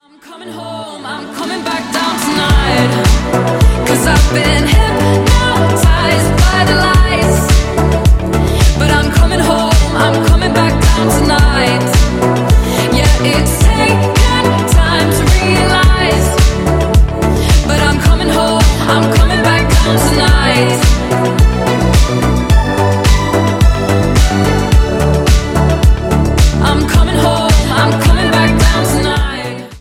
ジャンル(スタイル) NU DISCO / HOUSE